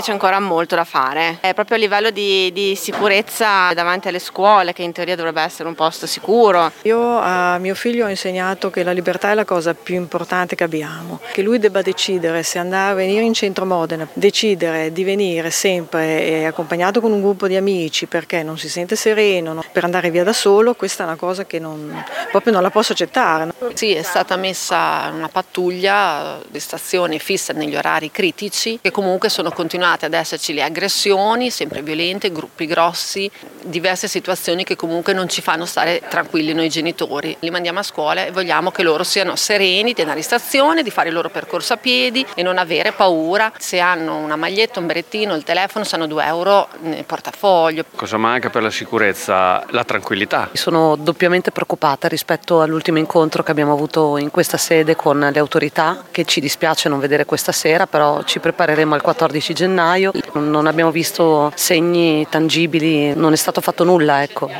Una 50ina di persone ha partecipato ieri sera all’incontro sulla sicurezza dei ragazzi promosso dal comitato Noi Reagiamo.
Ecco alcuni genitori presenti ieri sera:
Vox-incontro-sicurezza.mp3